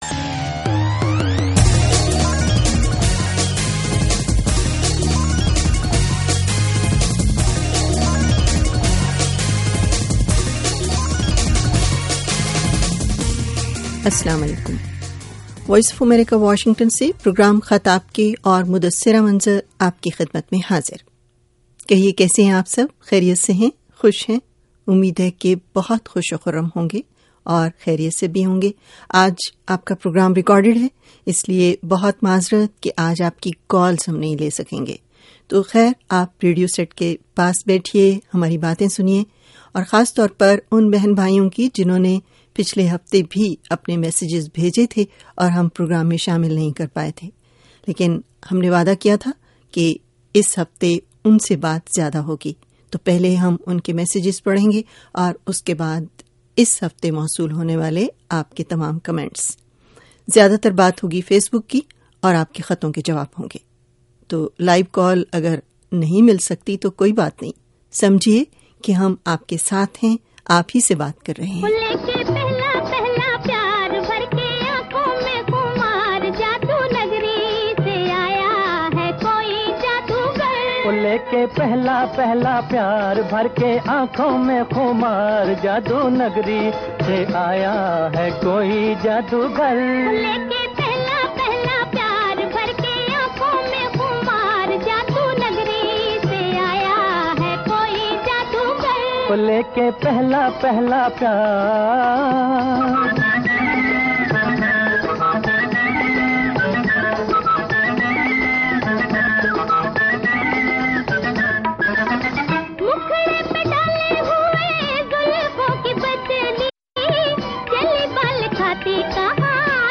پروگرام پھلے سے ریکارڈ کیا گیا تھا اس لئے کالز شامل نہیں کی جا سکیں